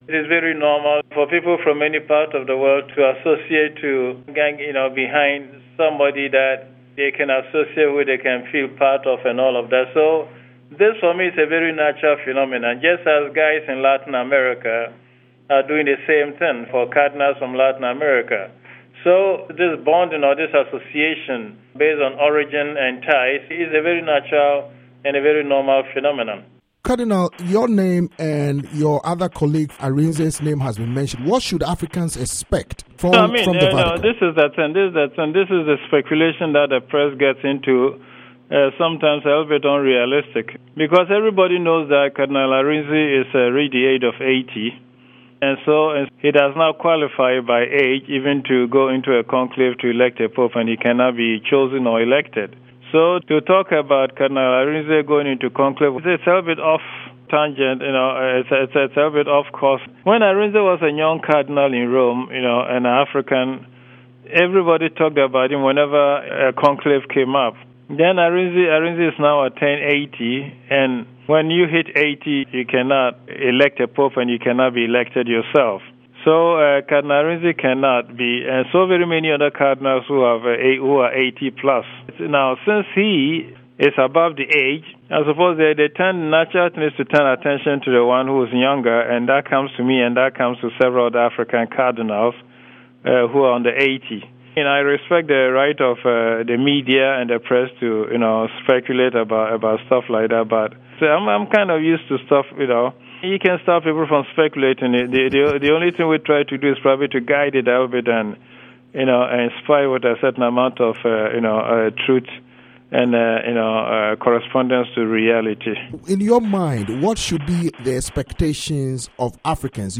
In an interview with VOA, Cardinal Peter Kodwo Appiah Turkson of Ghana says the selection of a pope is a sacred process that requires prayers and God’s guidance.